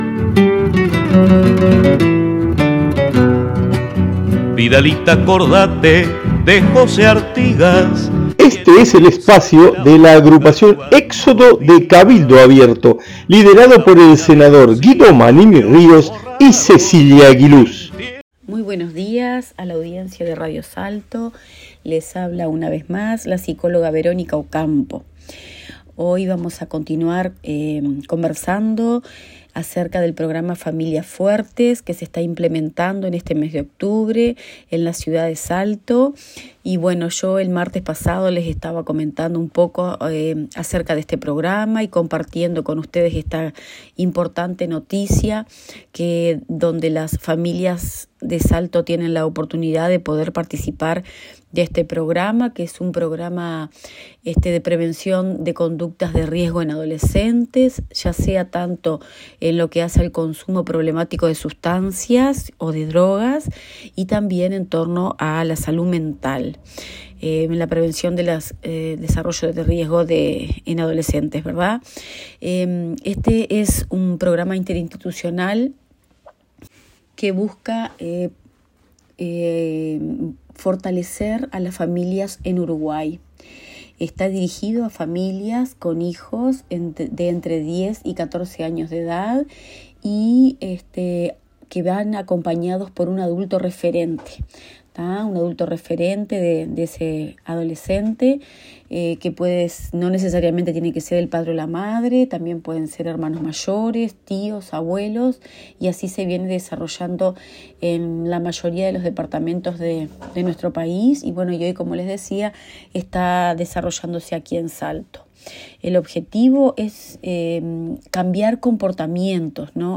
Audición radial de nuestra agrupación para Radio Salto(1120AM) del día 19 de octubre de 2023.